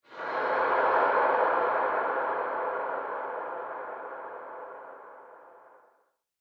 cave4.mp3